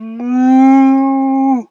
cow_2_moo_04.wav